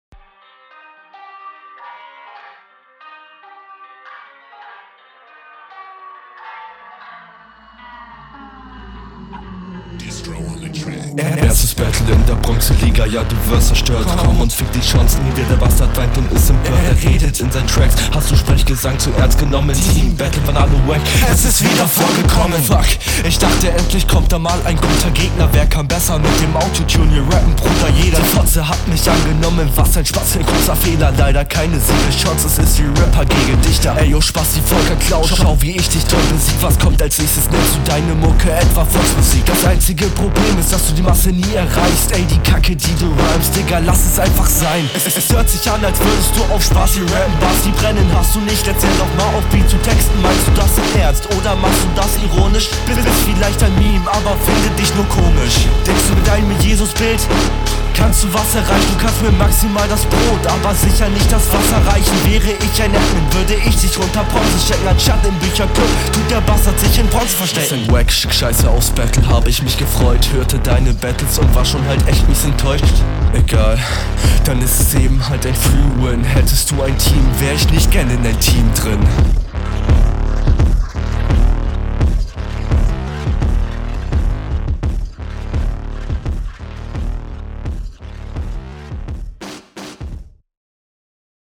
den beat zu picken is cringe vorallem weil du halt nicht an den originalflow ran …